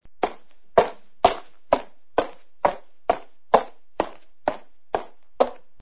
PASOS RAPIDOS PASOS FAST
Ambient sound effects
Pasos_rapidos_pasos_fast.mp3